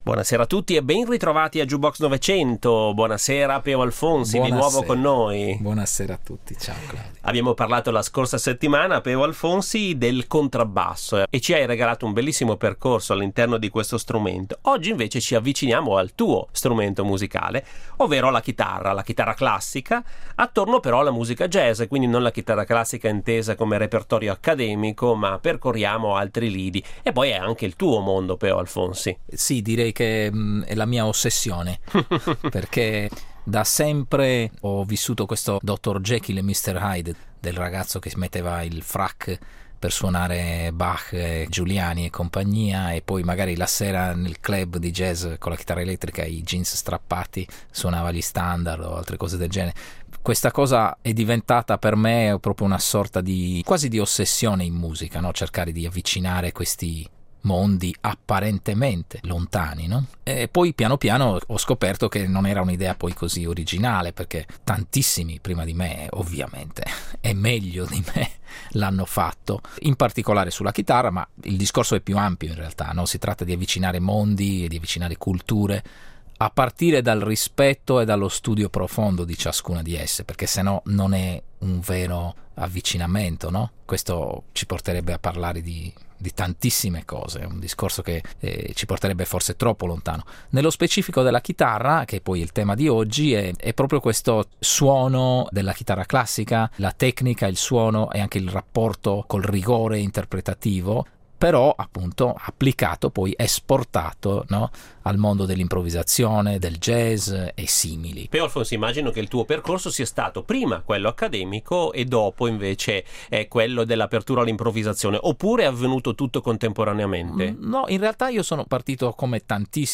La chitarra classica attorno al jazz